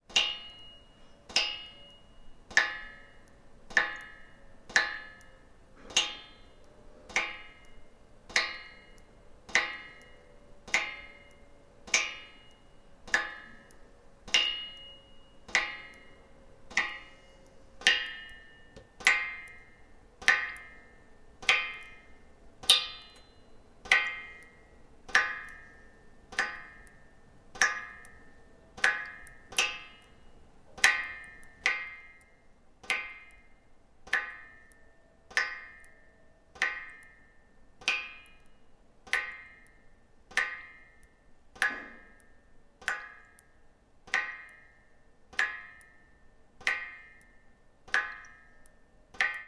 На этой странице собраны натуральные звуки воды из крана: от мягкого потока до отдельных капель.
Звук падающих капель воды на металл